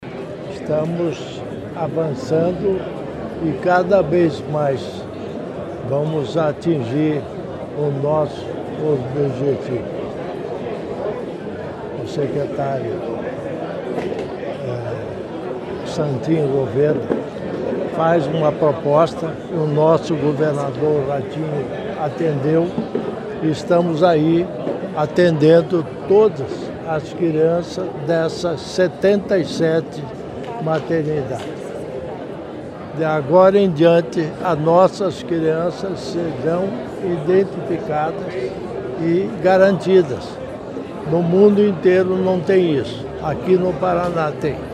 Sonora do vice-governador Darci Piana sobre o projeto de biometria neonatal Bebê ID